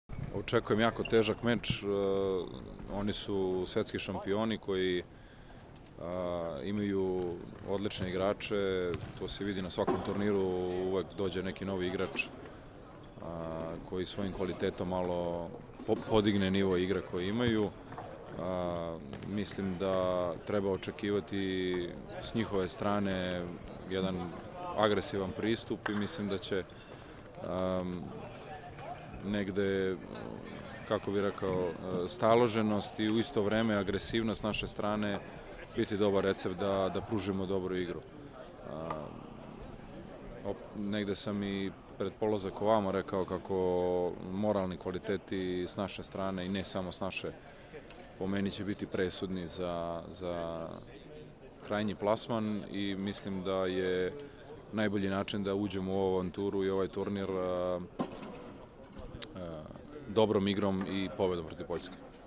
Muška seniorska reprezentacija Srbije trenirala je danas (ponedeljak, 4. januar) u dvorani “Maks Šmeling” od 12,00 – 14,00 časova, a pre toga, od 11,00 časova održana je konferencija za novinare, kojoj su prisustvovali treneri svih 8 reprezentacija učesnica turnira Evropskih kvalifikacija za OI 2016, koji će se odigrati od 5. – 10. januara.